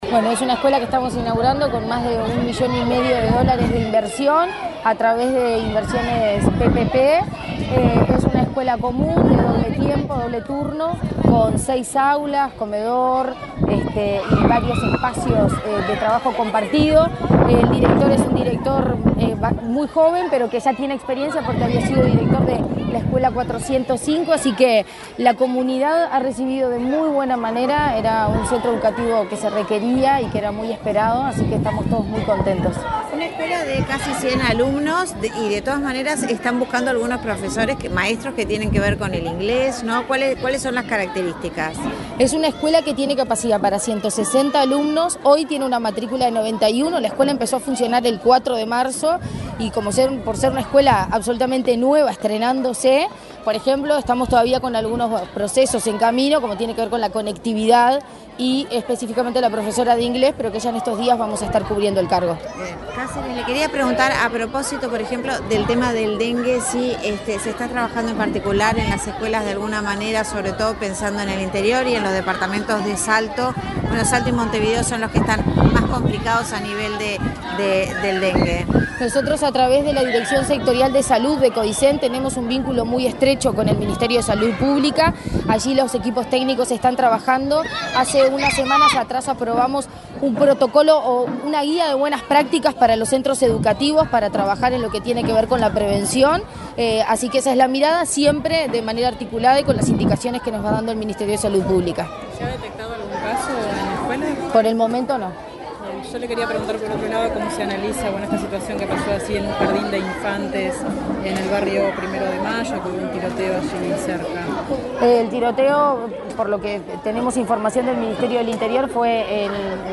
Declaraciones de la presidenta de ANEP, Virginia Cáceres
Declaraciones de la presidenta de ANEP, Virginia Cáceres 12/04/2024 Compartir Facebook X Copiar enlace WhatsApp LinkedIn La presidenta de la Administración Nacional de Educación Pública (ANEP), Virginia Cáceres, dialogó con la prensa, luego de participar en la inauguración de la escuela n.º 409 de Montevideo.